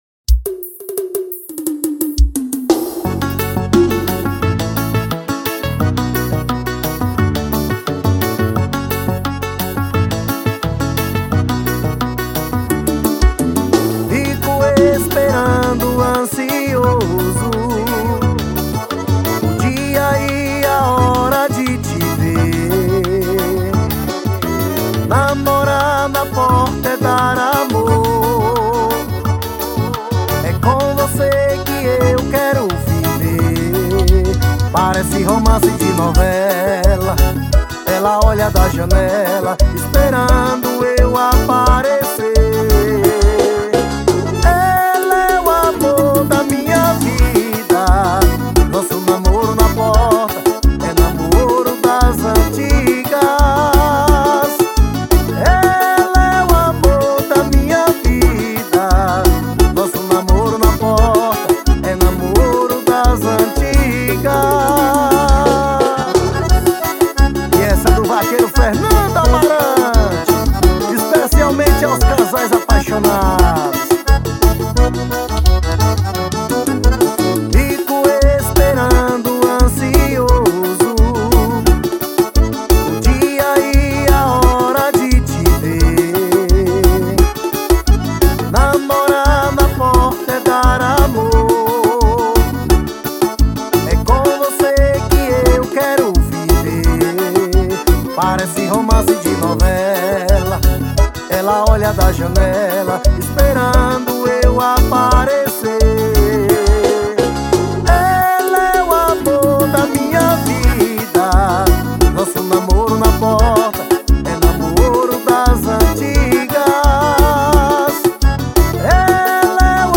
EstiloPiseiro